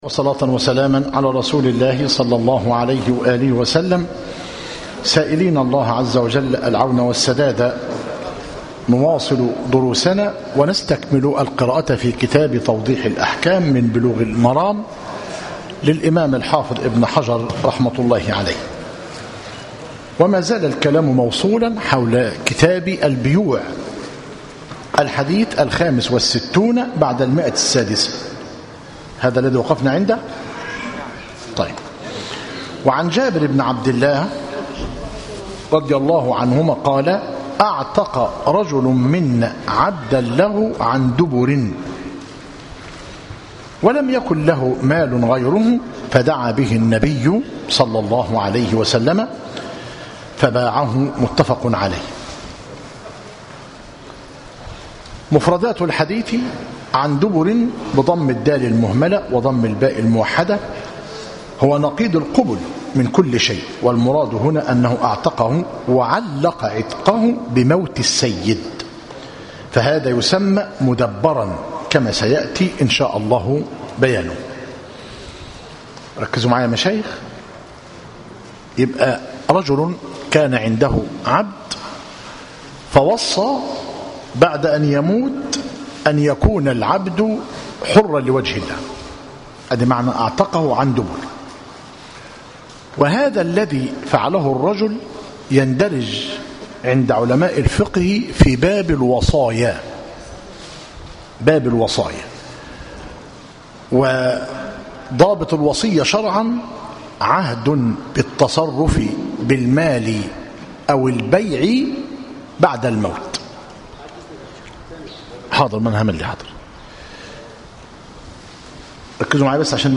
مسجد التوحيد - ميت الرخا - زفتى - غربية - المحاضرة العشرون بعد المئة الأولى